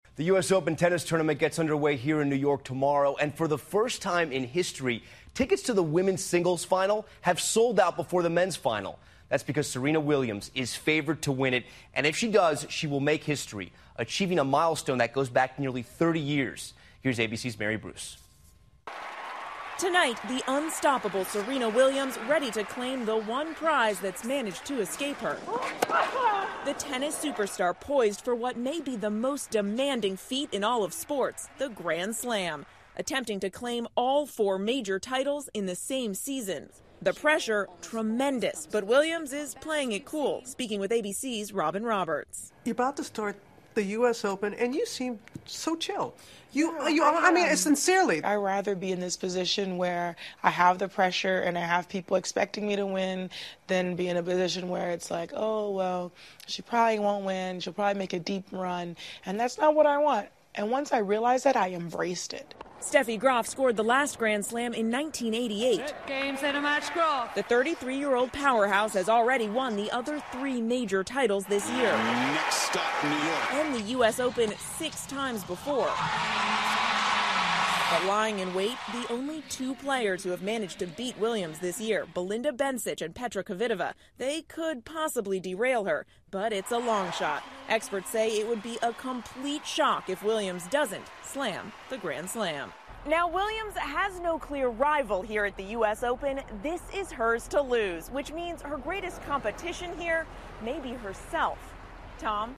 访谈录 小威廉姆斯冲击年度全满贯 听力文件下载—在线英语听力室